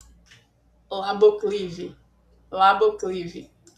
Ritmo médio, trilha leve e animada